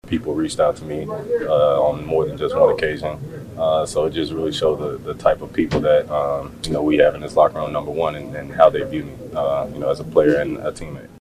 The veteran wide receiver was grateful that his teammates stayed in touch while he was away.